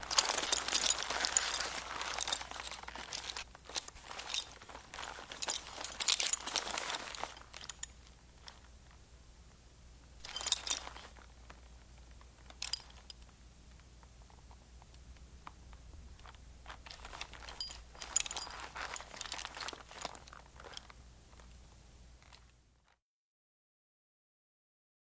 Horse Bridle And Milling On Gravel, Foley Effects